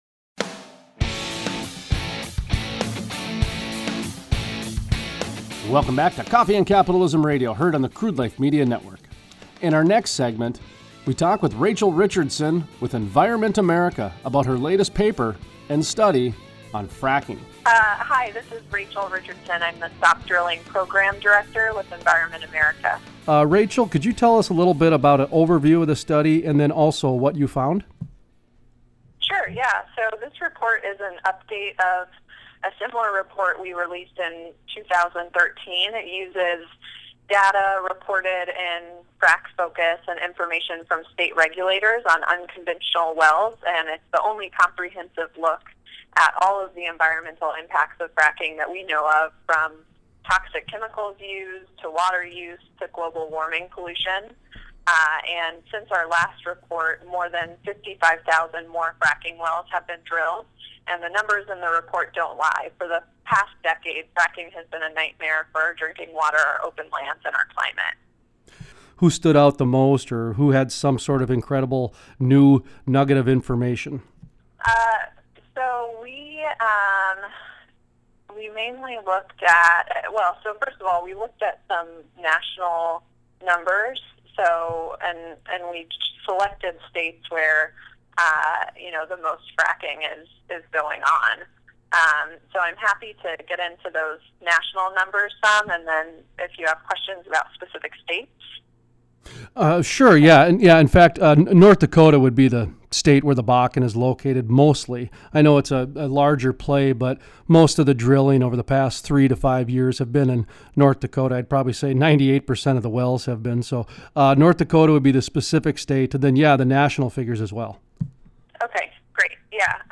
Full Length Interview